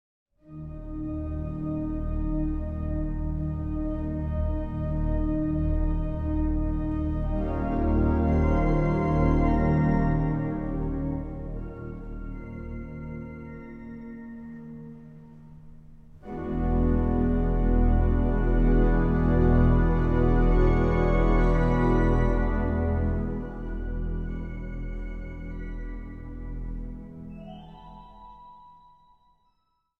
Instrumentaal | Orgel